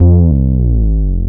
12BASS01  -L.wav